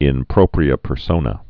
(ĭn prōprē-ə pər-sōnə)